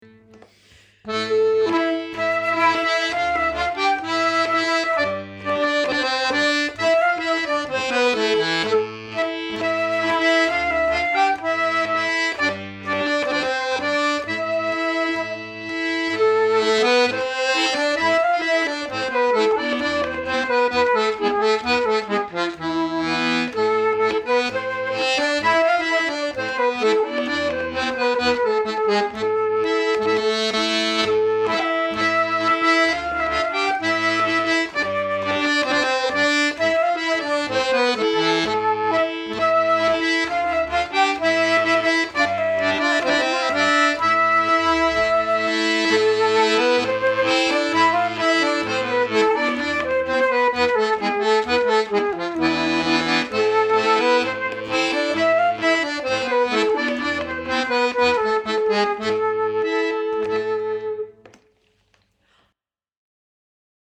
4 nouveaux morceaux pour l'année : un nouveau rondeau qui vient remplacer notre las hemnas et la pieternelle qui vient remplacer la scottich des freres lambert (c'est une musique/danse du nord de France)  et Uskudara, un morceau turc pouvant se danser en scottich, et une tarentelle A Lu Mircatu.